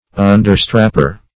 Search Result for " understrapper" : The Collaborative International Dictionary of English v.0.48: Understrapper \Un"der*strap`per\, n. A petty fellow; an inferior agent; an underling.